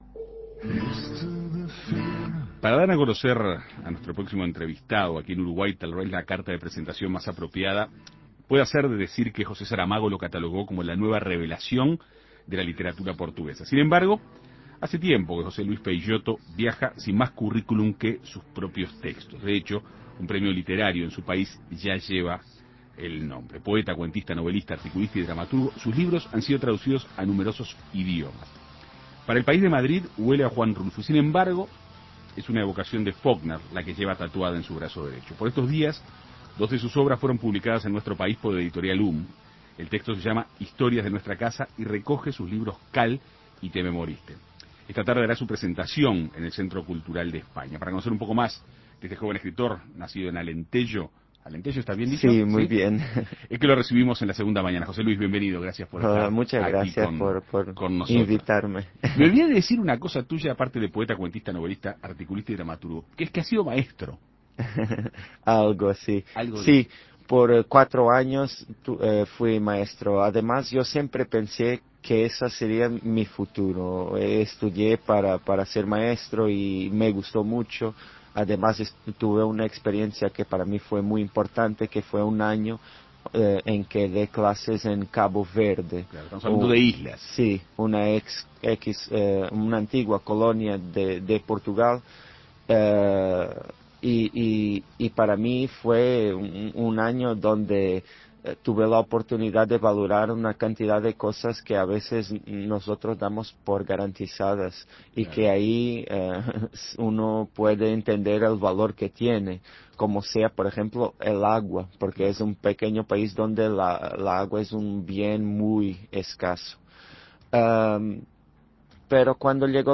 Recientemente, dos de sus obras fueron publicadas en Uruguay por Editorial HUM. El texto se llama Historias de nuestra casa y recoge sus libros Cal y Te me moriste. Este martes hará su presentación en el Centro Cultural de España, pero antes dialogó con En Perspectiva Segunda Mañana.